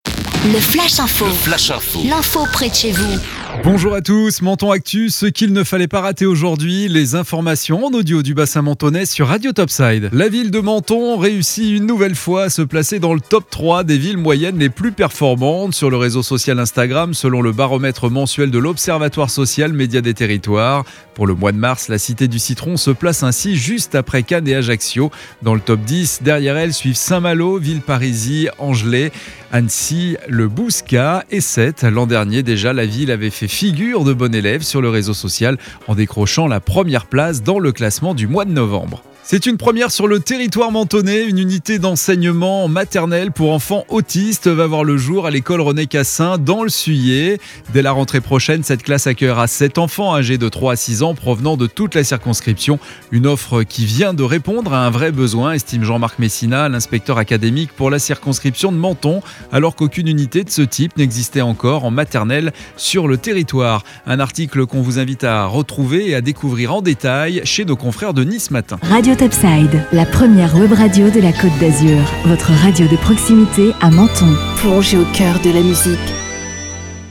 Menton Actu - Le flash info du mercredi 14 avril 2021